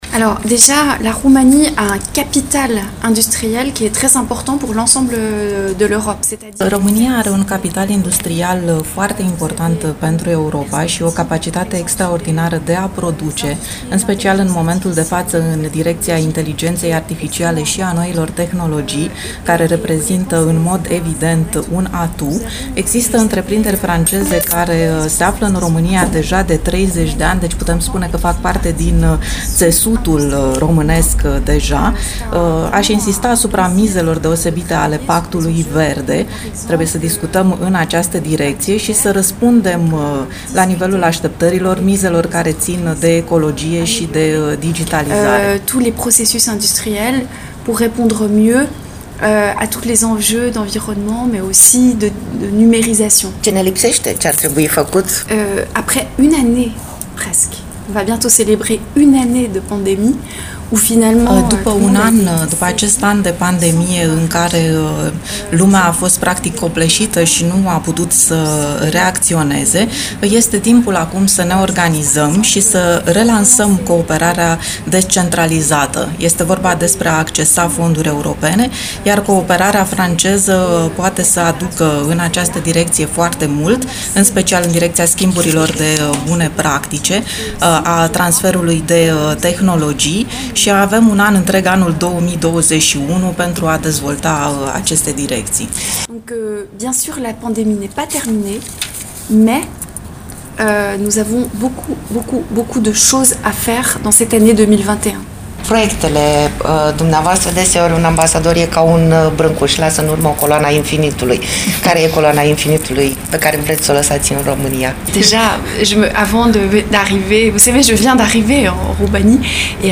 Interviul integral
RFI-Interviu-ambasadorul-Frantei-la-iasi.mp3